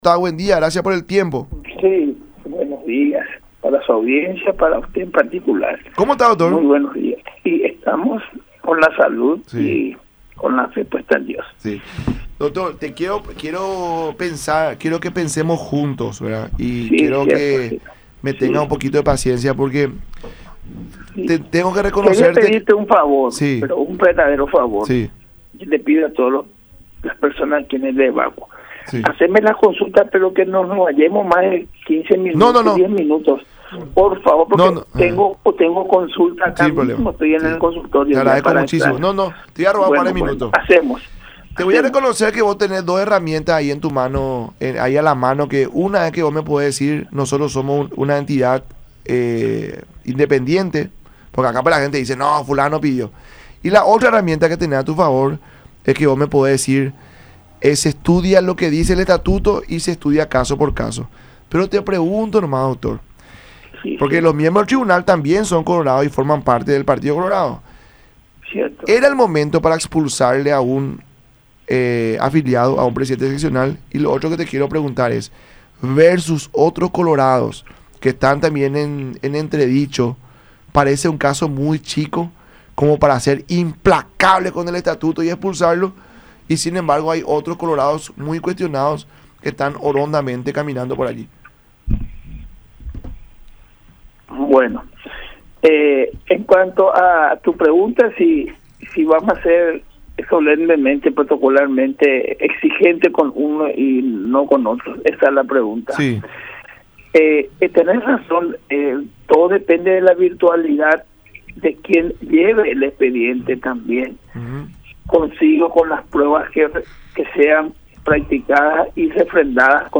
en diálogo con La Mañana De Unión por Unión TV y radio La Unión